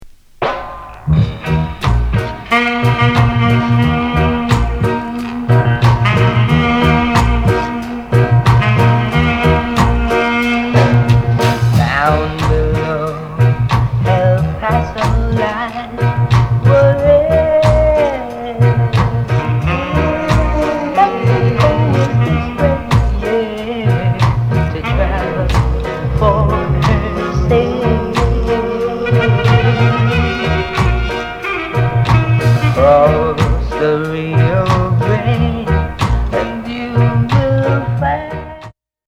イナタ過ぎるコーラス、太いベース・ライン、マリアッチ情緒満点な
どこまでもムーディなテナー・サックス。